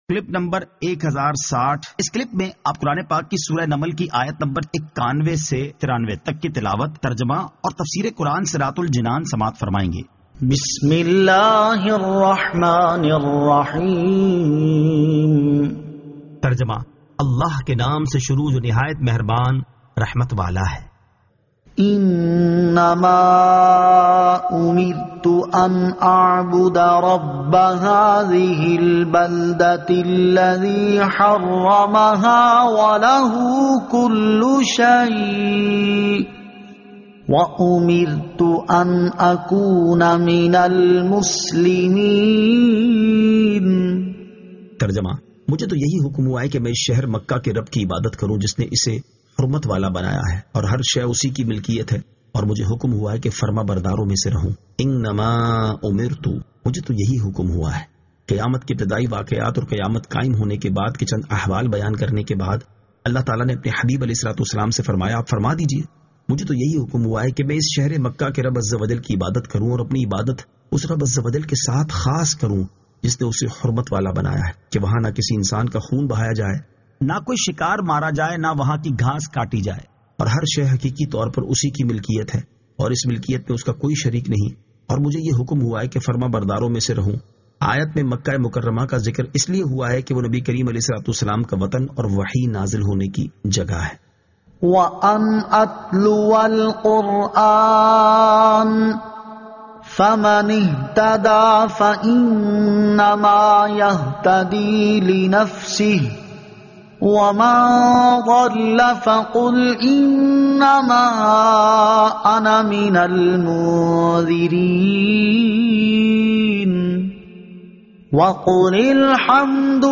Surah An-Naml 91 To 93 Tilawat , Tarjama , Tafseer